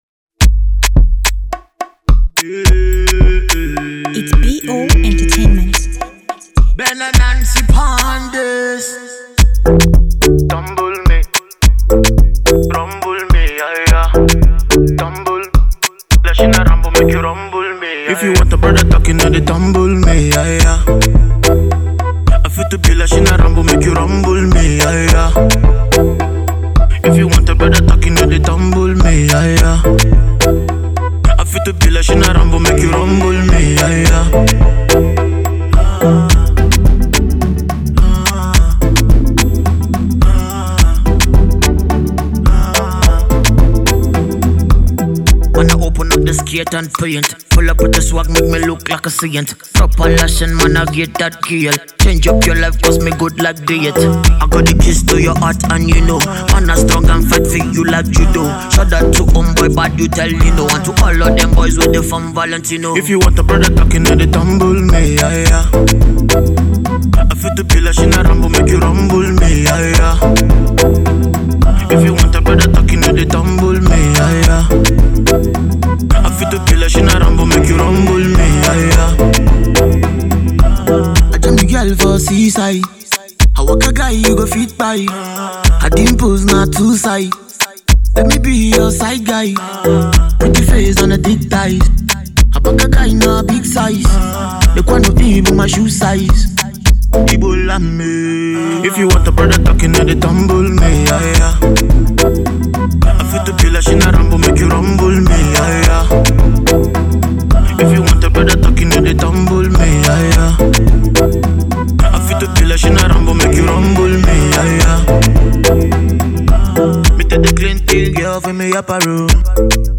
dancehall
has that infectious sound that will make you move your body.